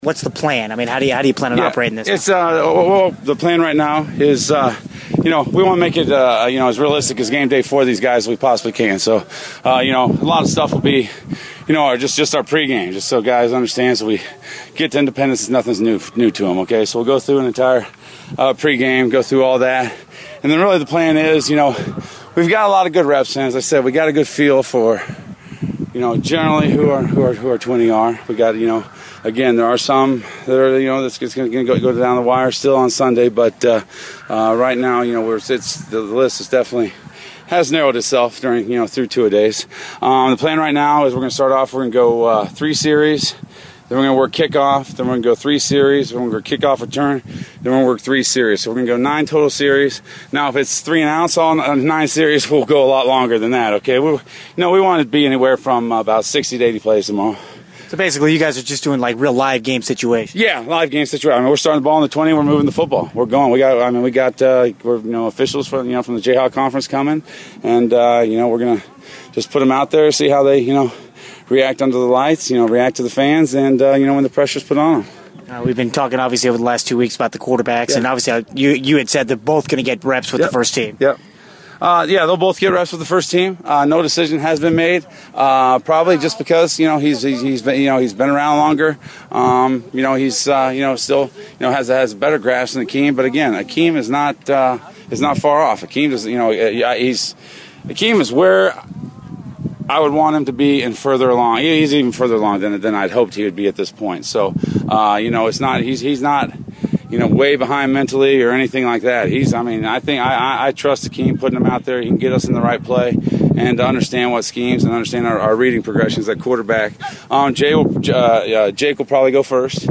Thursday’s Interviews